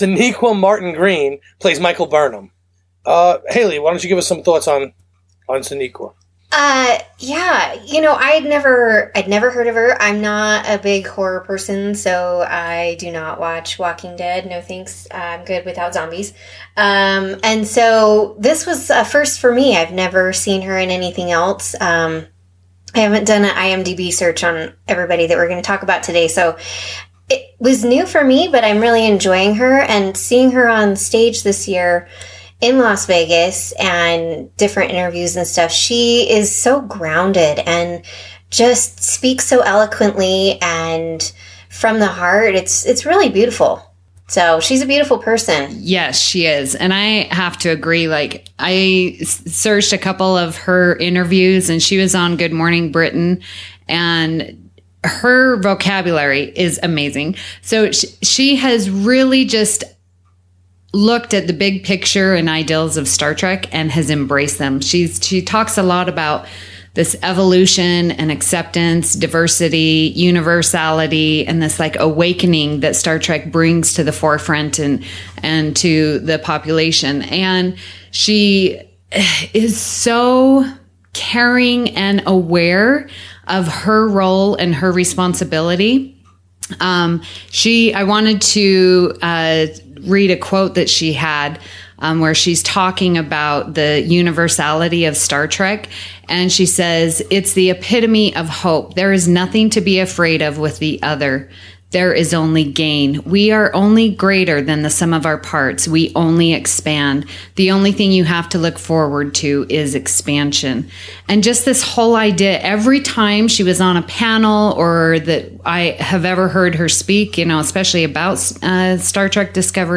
This discussion takes place on one of my favorite PodCasts, TrekFM-The Edge where they host.